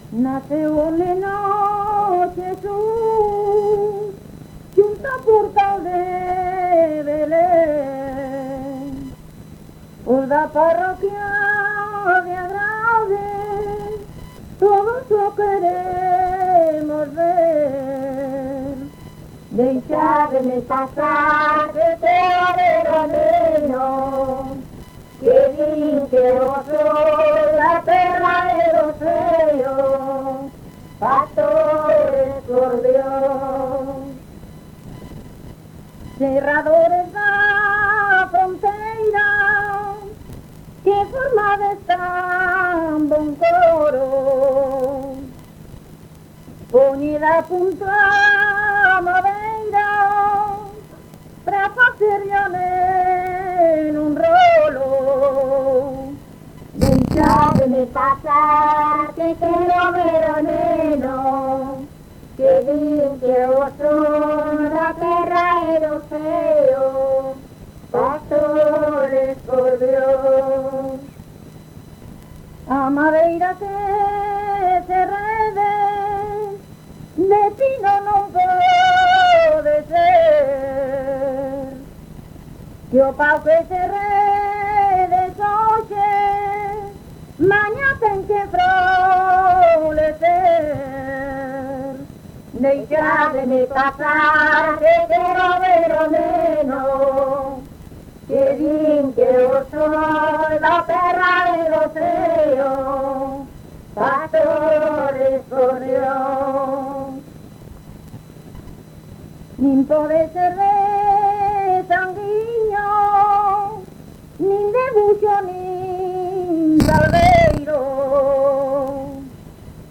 Áreas de coñecemento: LITERATURA E DITOS POPULARES > Cantos narrativos
Lugar de compilación: Chantada - A Grade (San Vicente) - Quintá
Soporte orixinal: Casete
Instrumentación: Voz
Instrumentos: Voz feminina